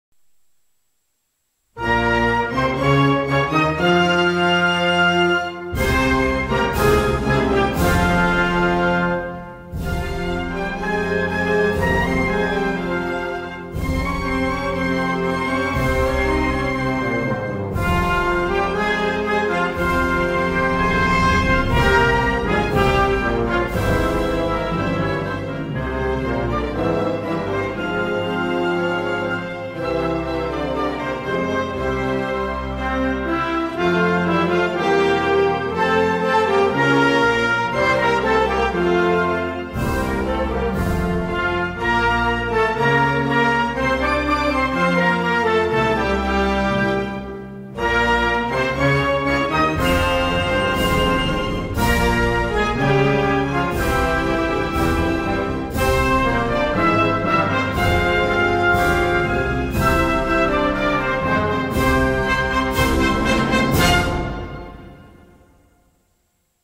на трубе